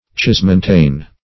Cismontane \Cis*mon"tane\, a. [Pref. cis- + L. mons mountain.]